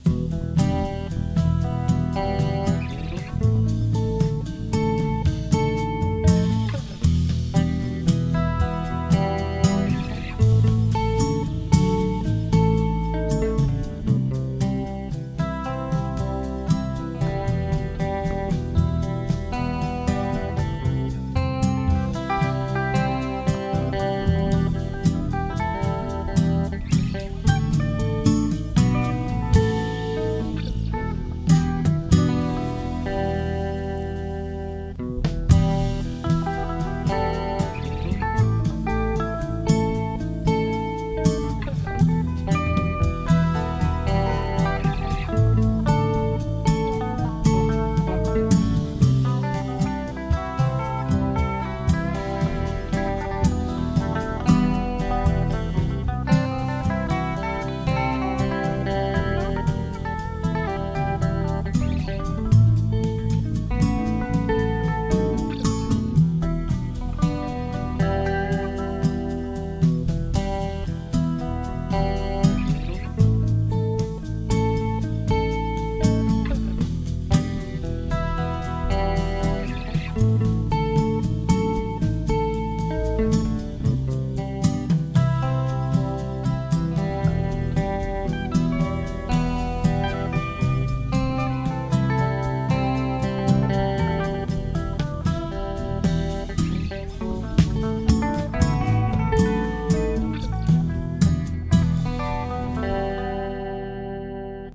• Instrumentation : Guitare acoustique solo.
pour préserver la dynamique naturelle du jeu.